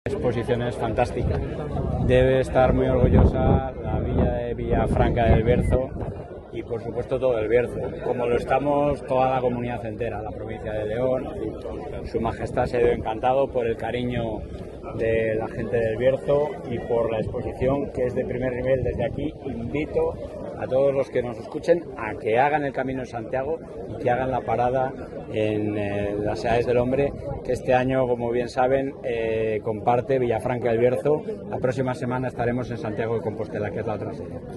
Valoración del presidente de la Junta.